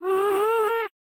Minecraft Version Minecraft Version snapshot Latest Release | Latest Snapshot snapshot / assets / minecraft / sounds / mob / happy_ghast / ambient10.ogg Compare With Compare With Latest Release | Latest Snapshot